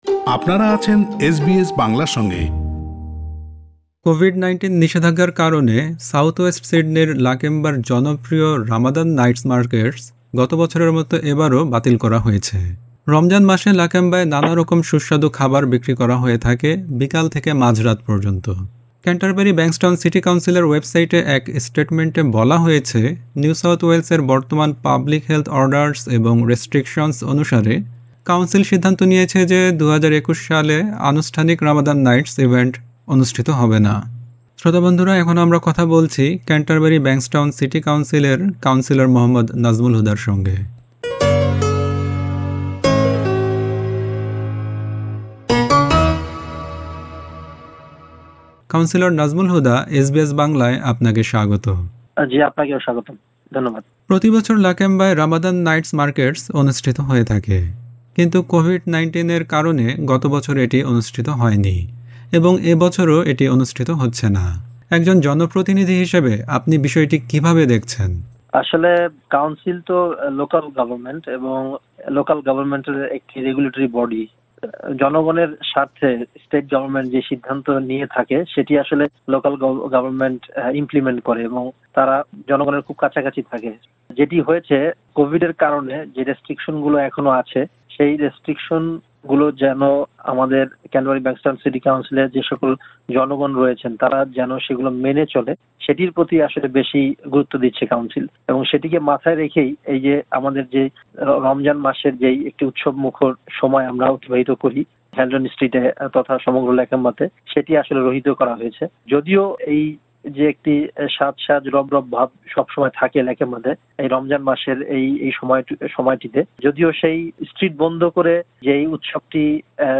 কোভিড-১৯ নিষেধাজ্ঞার কারণে সাউথ-ওয়েস্ট সিডনির লাকেম্বার জনপ্রিয় রামাদান নাইটস মার্কেটস গত বছরের মতো এবারও বাতিল করা হয়েছে। ক্যান্টারবেরি ব্যাংকসটাউন সিটি কাউন্সিলের কাউন্সিলর মোহাম্মদ নাজমুল হুদা কথা বলেছেন এসবিএস বাংলার সঙ্গে।